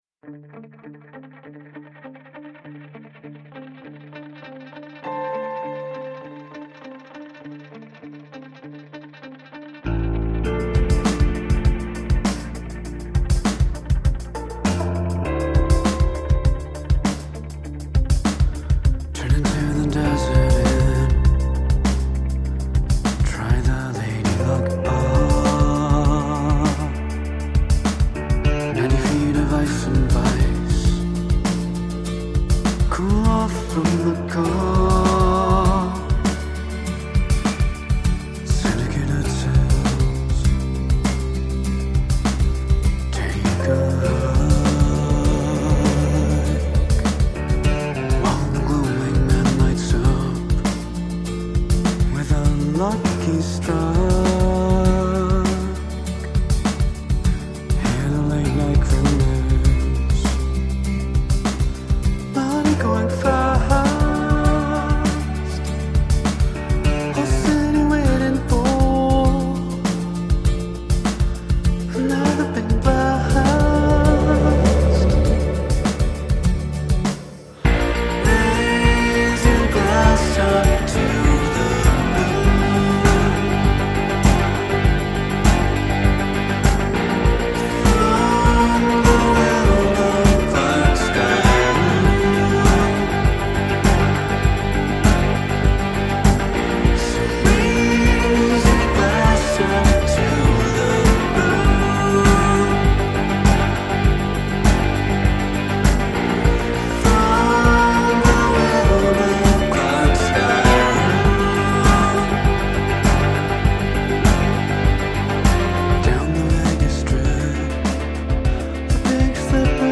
post-punk revival
primitive tribal beats